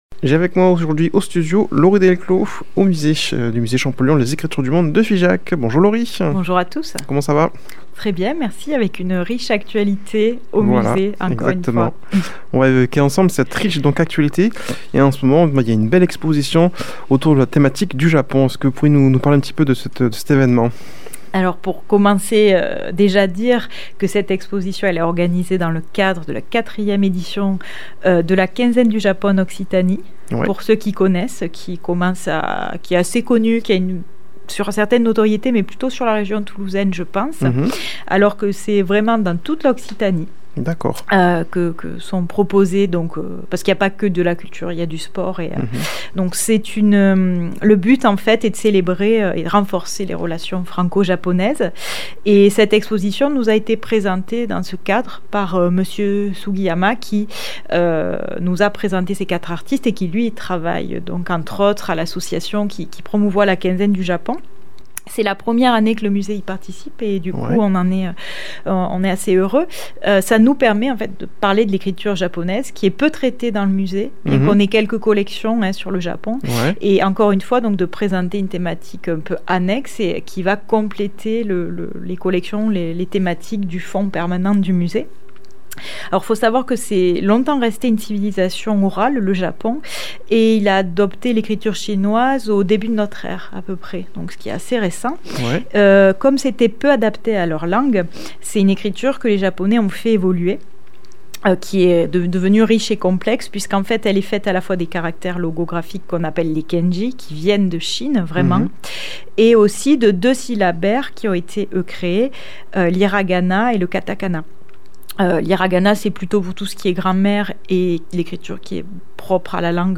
invitée au studio
Présentateur